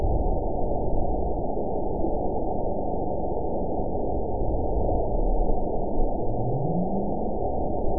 event 911396 date 02/24/22 time 18:54:53 GMT (3 years, 2 months ago) score 8.90 location TSS-AB04 detected by nrw target species NRW annotations +NRW Spectrogram: Frequency (kHz) vs. Time (s) audio not available .wav